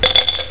CLINK03.mp3